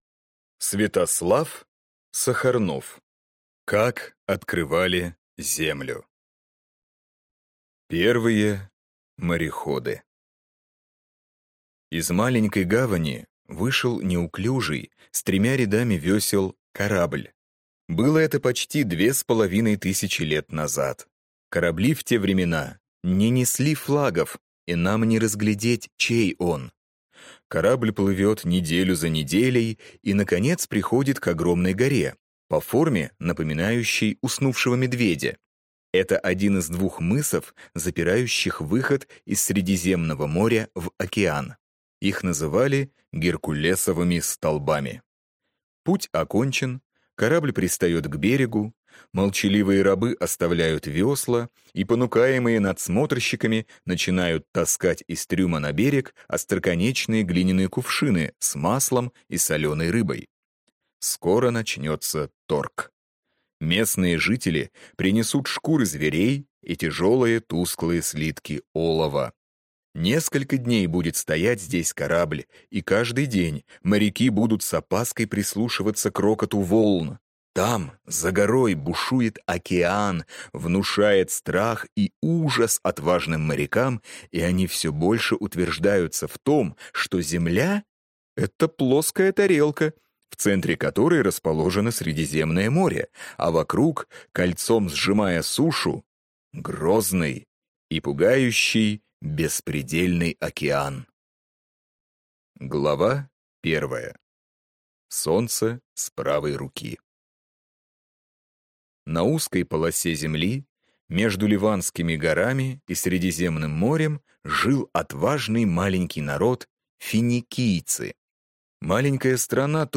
Аудиокнига Как открывали Землю | Библиотека аудиокниг